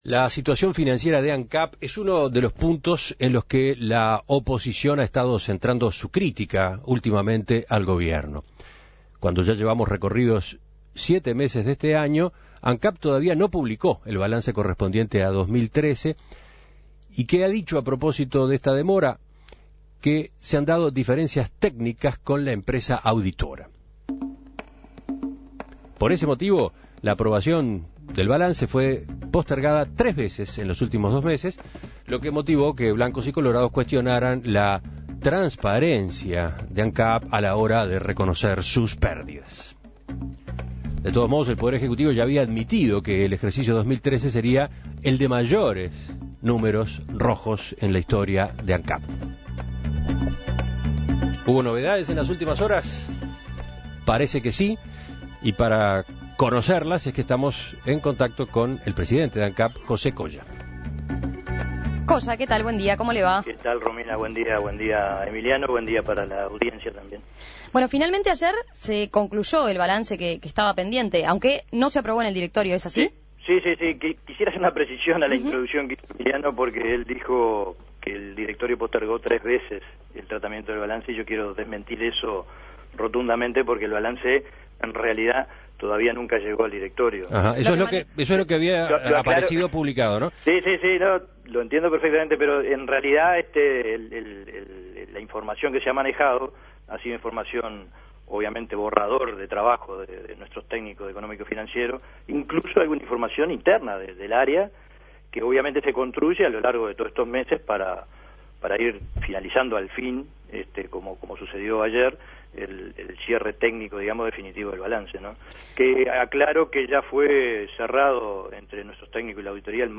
Como había estimado el Poder Ejecutivo, las pérdidas del ente fueron las mayores en su historia. Para explicar esta cifra En Perspectiva entrevistó al presidente del Directorio de Ancap, José Coya, quien reiteró que la diferencia de cambio fue uno de los factores principales que incidió en esta cifra.